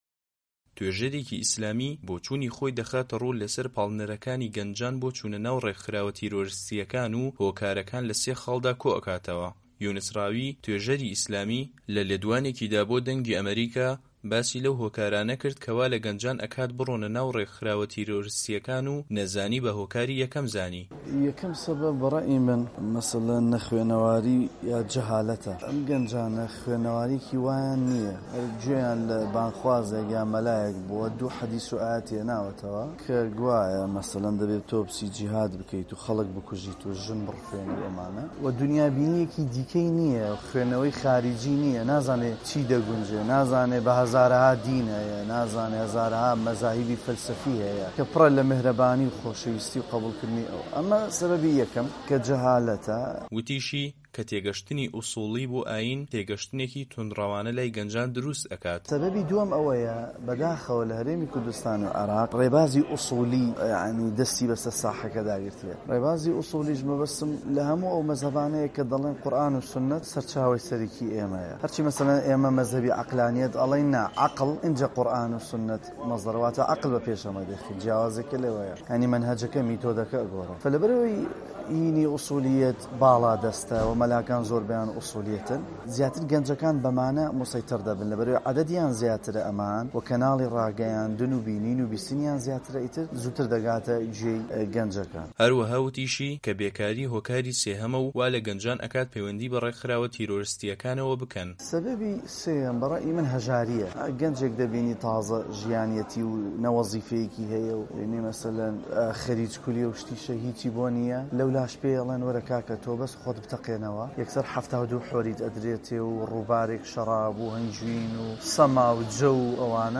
لەمەڕ چوونی گەنجان بۆ ناو ڕێکخراوە تیرۆریستییەکان، توێژەرێکی ئیسلامی بۆچوونی خۆی ئەخاتە ڕوو لەسەر هۆکارەکان و لە سێ خاڵدا کۆیان ئەکاتەوە